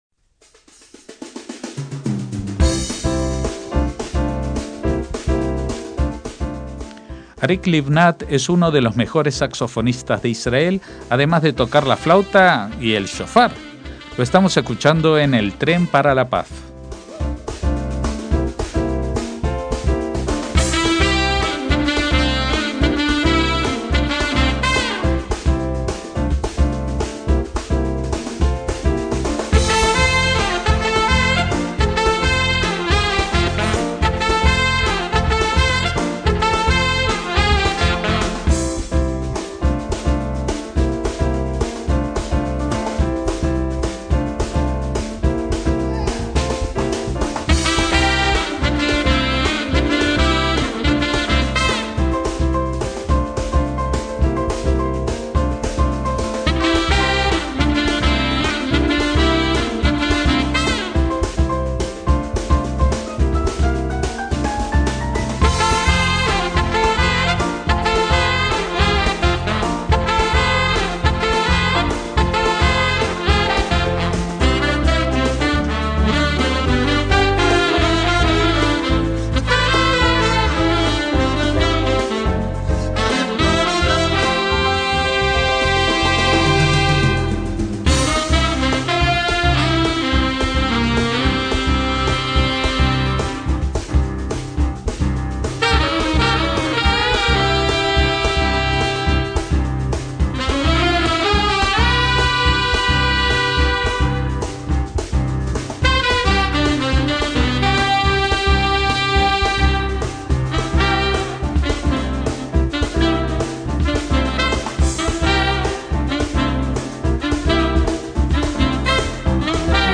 saxofonista
bajo
batería y percusiones
teclados
saxo y flauta
guitarra